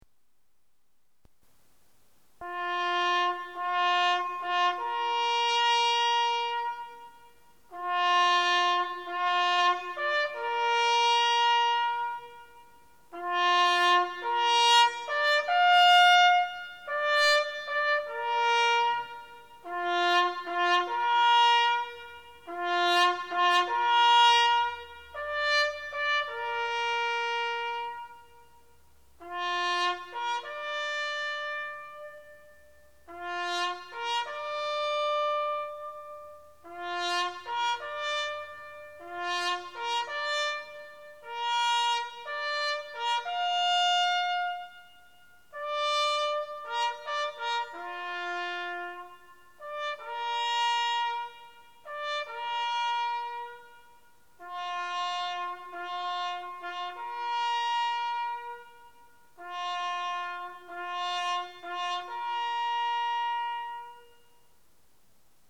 1. dakikalık saygı duruşu için siren sesi